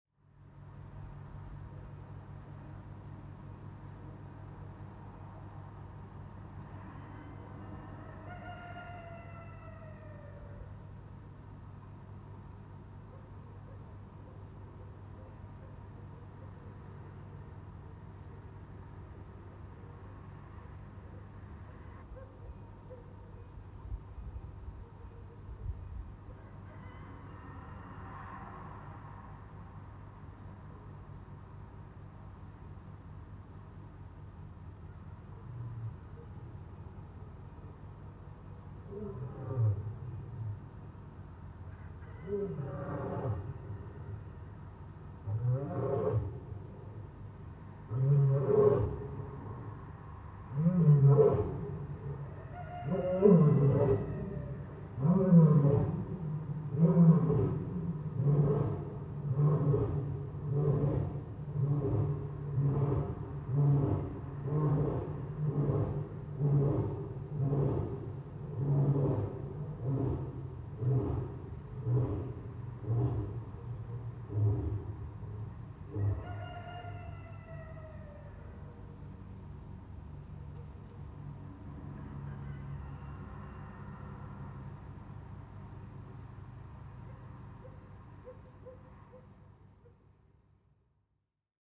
Morning dawn in Tirana, Albania.
Balcony recording: morning rooster, other background animal sounds possibly dogs, at some point the main reason i cannot sleep: the lion downstairs caged in the zoo.
The juxtaposition of the familiar sound of the rooster signaling morning with the unexpected presence of the lion creates a surreal and thought-provoking atmosphere. The lion often called the king of the jungle-is roaring together with the morning-specialist-rooster in the dawn in a city where it does not belong.